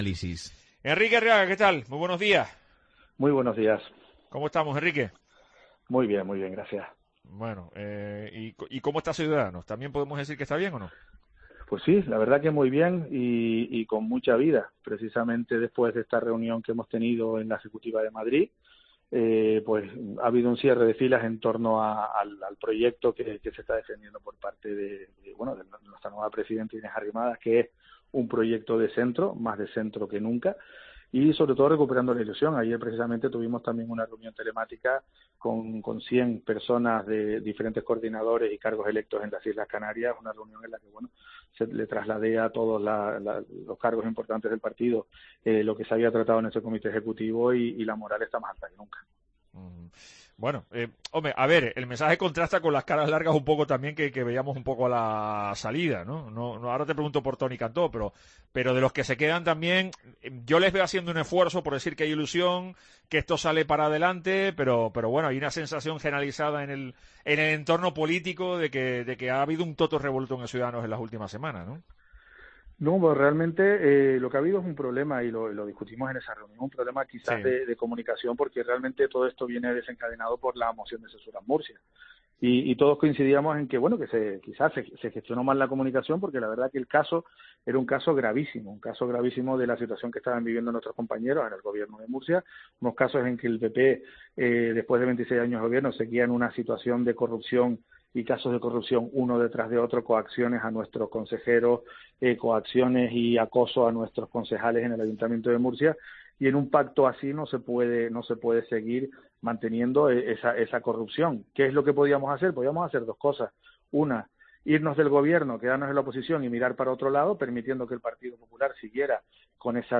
Un Enrique Arriaga más sincero que nunca, ha valorado hoy, en La Mañana de COPE Tenerife, diversas cuestiones de actualidad que tienen que ver con su partido a nivel nacional, como por ejemplo, la celebración de las próximas elecciones autonómicas en la Comunidad de Madrid.